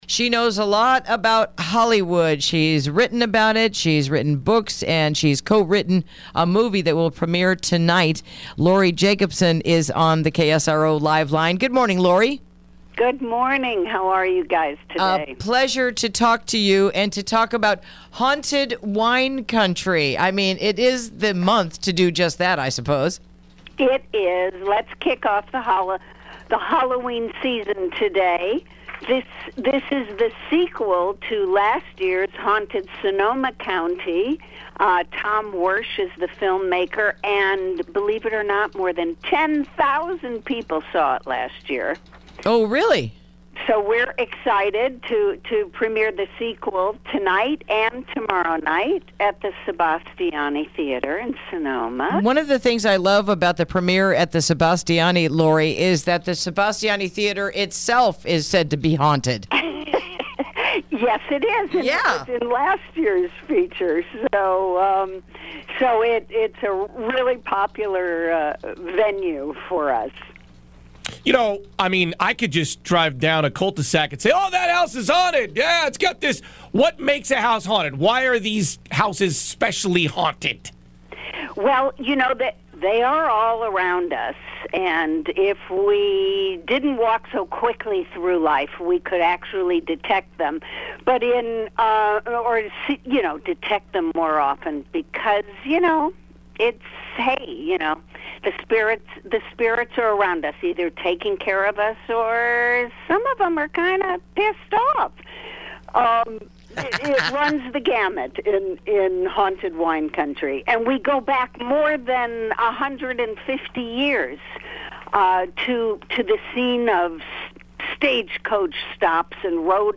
Interview: Haunted Wine Country